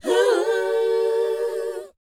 WHOA F D U.wav